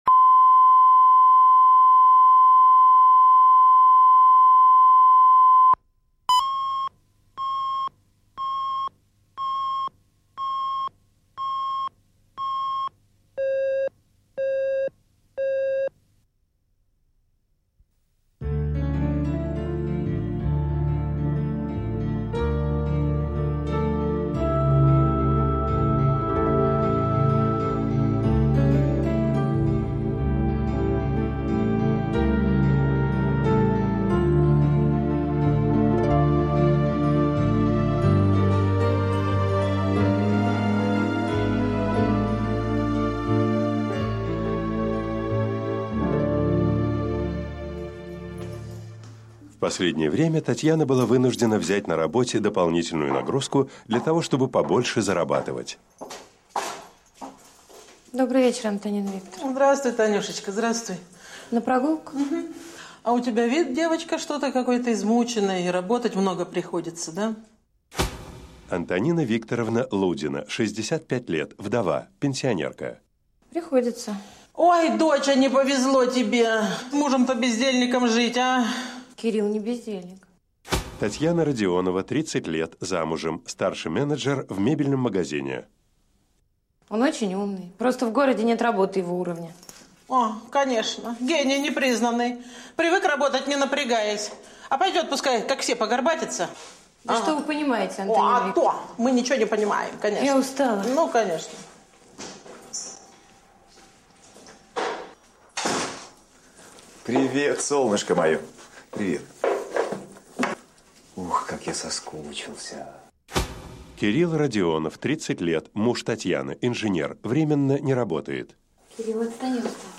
Аудиокнига Дорогой | Библиотека аудиокниг